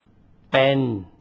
þen